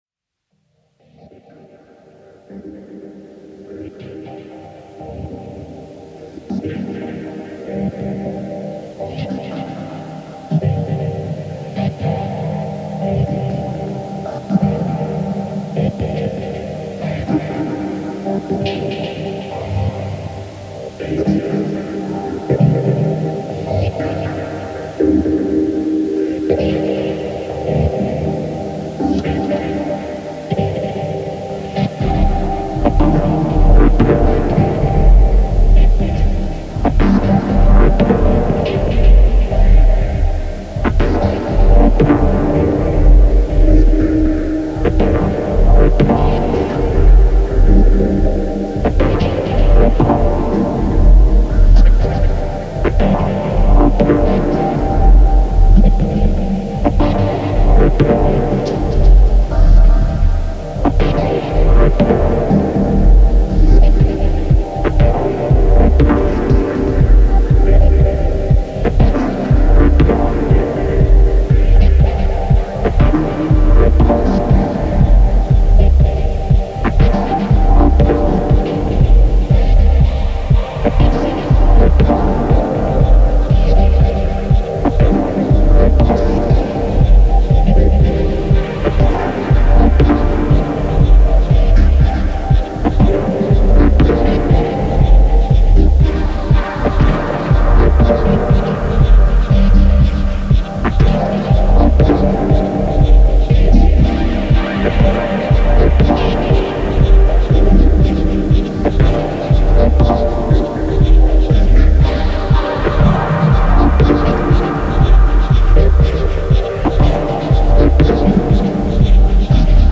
Genre: Leftfield/Dub Techno/Ambient.